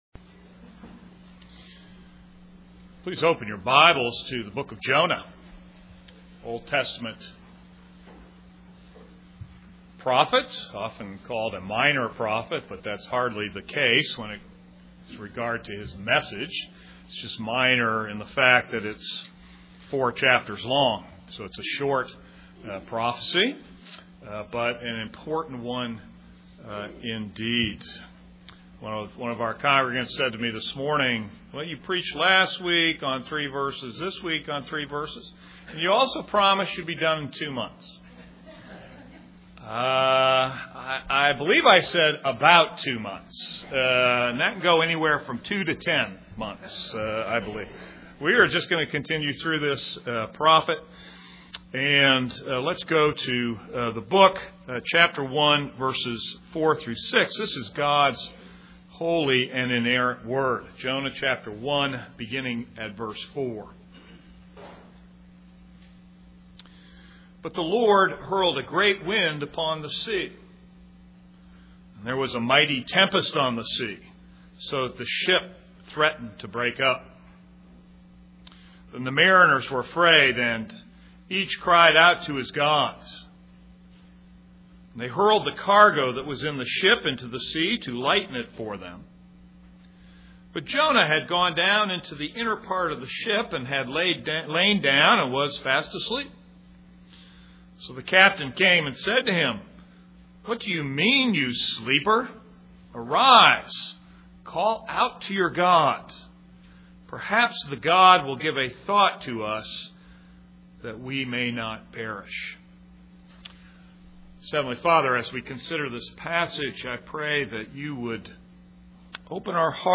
This is a sermon on Jonah 1:4-6.